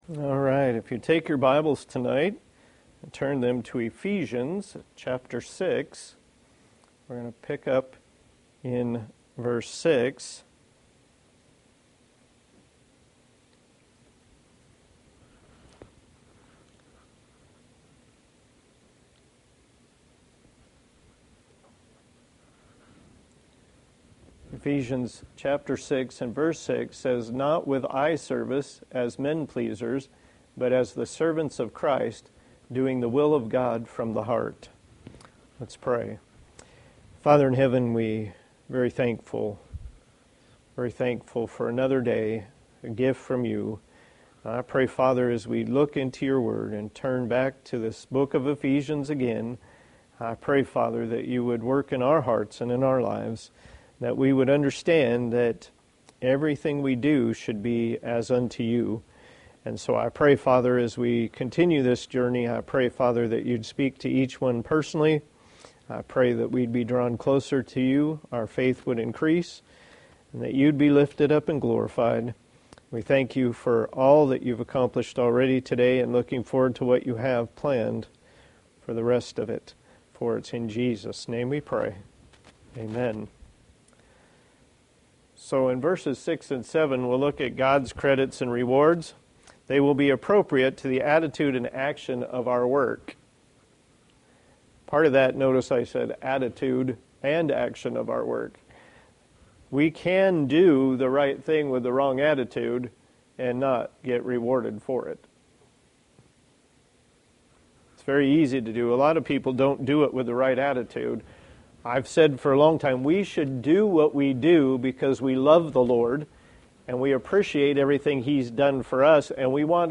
Passage: Ephesians 6:6 Service Type: Sunday Evening